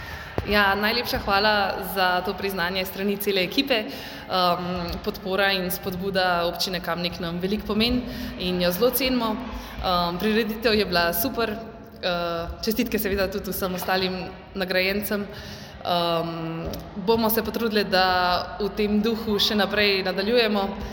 Zvočna izjava